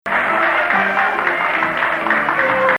Ajam 4